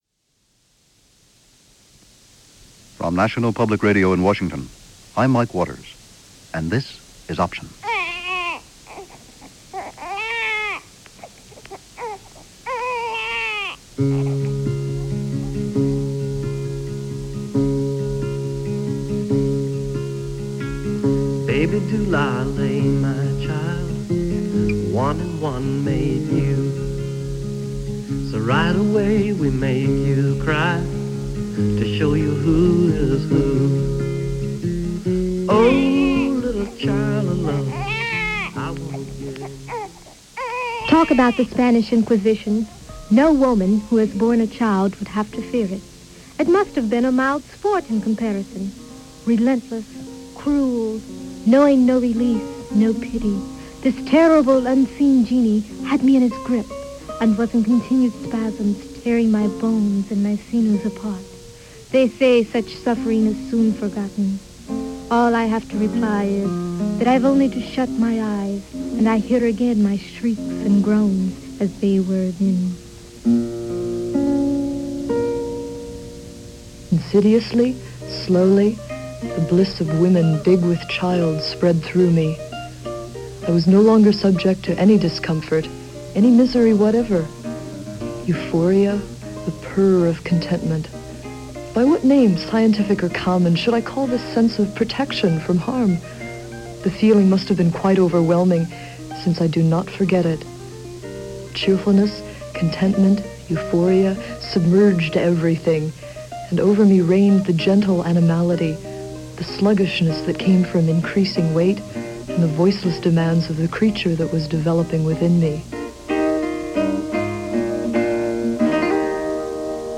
In this documentary, produced by National Public Radio in 1975, new methods of childbirth were being looked at – methods which were less evasive than before; the hospital room and the customary bellow-inducing slap, being replaced by home births -midwives, submersion in water – a whole host of new and ancient ways of introducing the newborn to the new world.